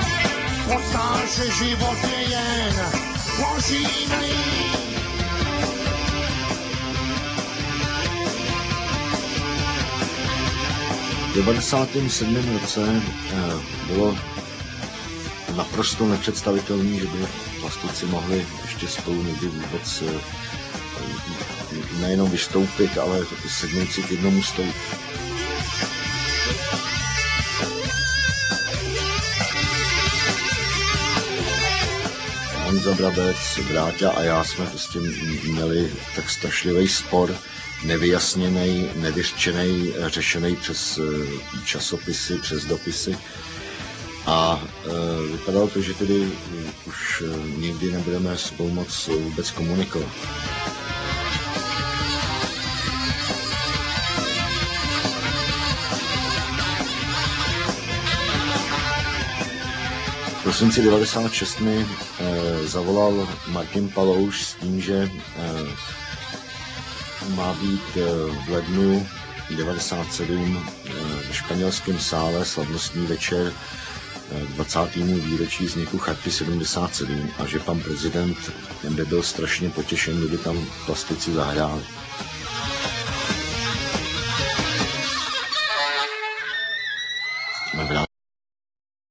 cast: underground music group The Plastic People of the Universe